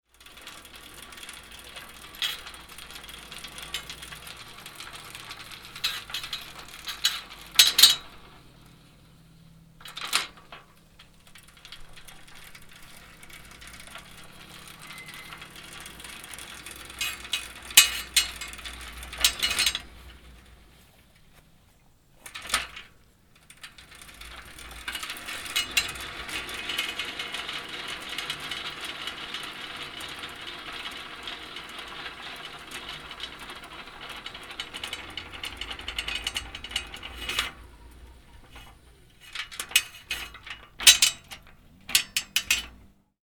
Звук Кручение педалей старого велосипеда (велосипед Украина) (00:43)